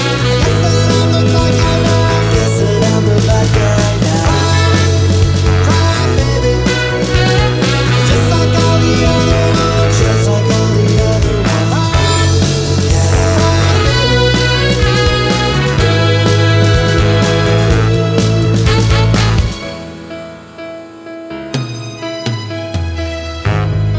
no Backing Vocals Pop (2000s) 3:28 Buy £1.50